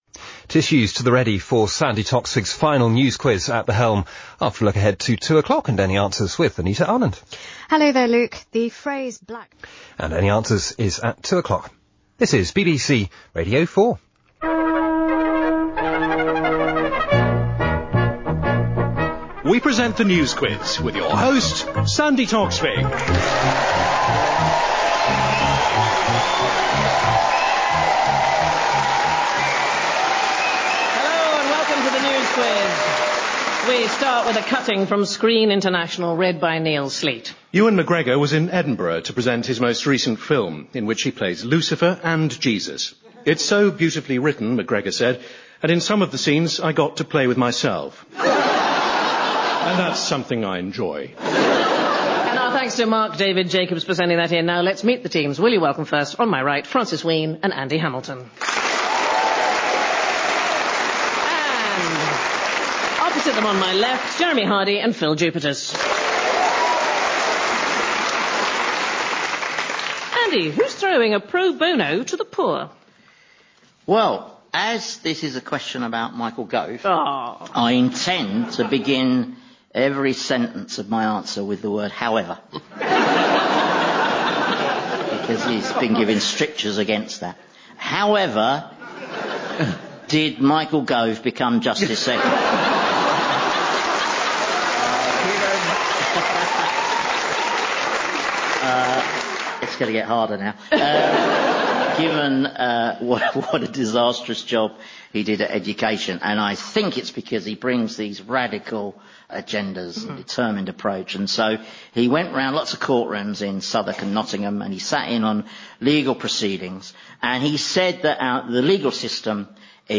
After nine years, Sandi Toksvig hosted her final edition of 'The News Quiz' on BBC Radio 4 in June 2015.